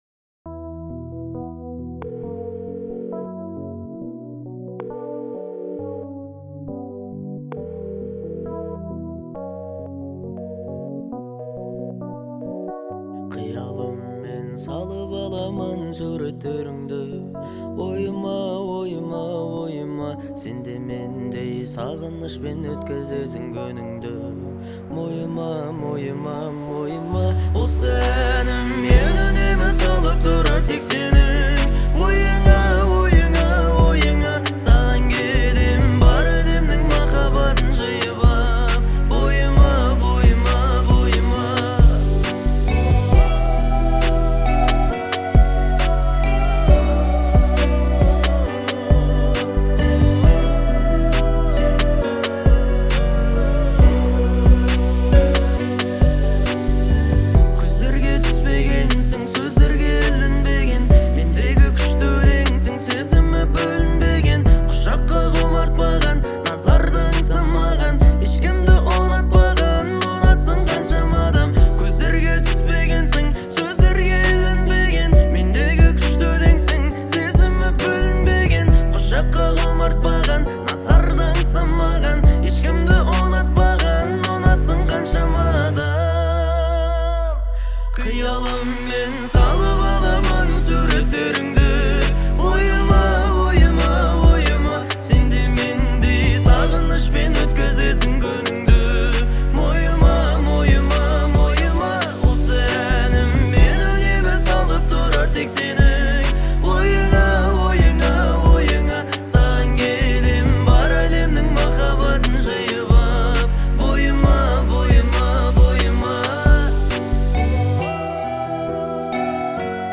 это трек в жанре казахской поп-музыки
выразительным вокалом и искренним исполнением